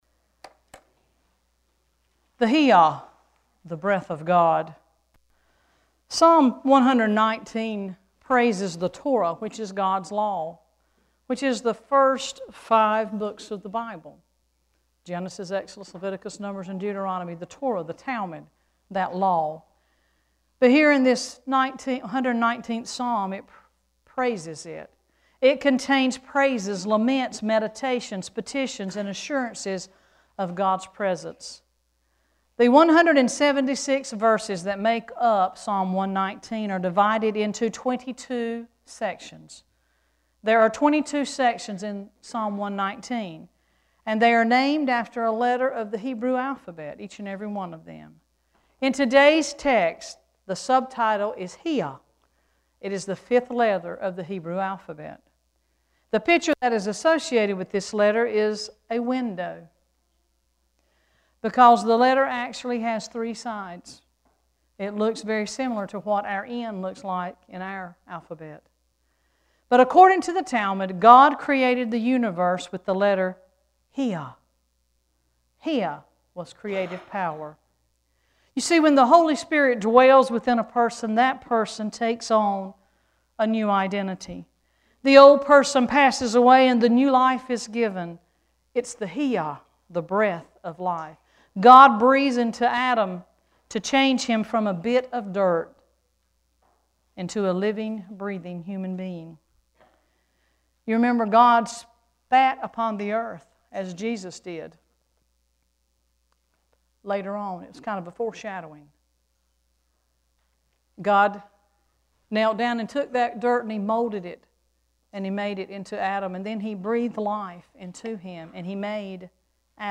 2-23-14-sermon.mp3